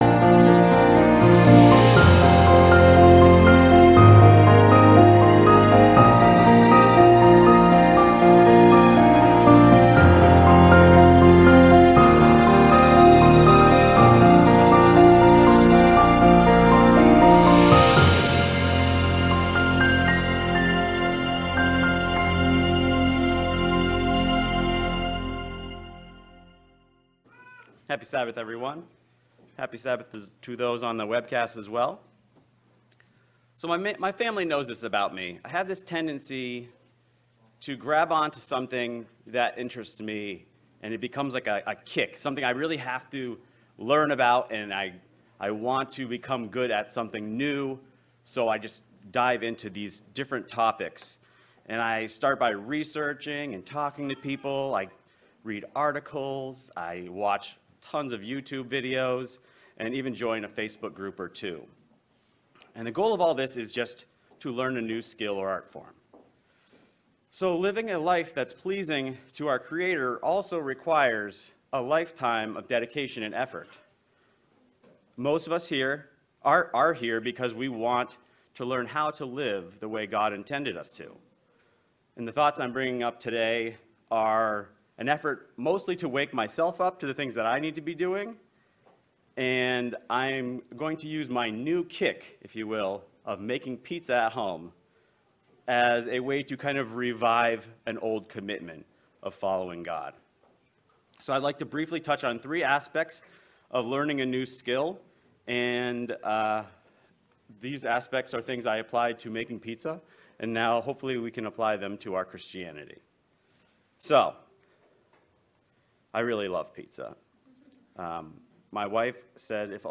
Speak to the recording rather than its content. Given in Worcester, MA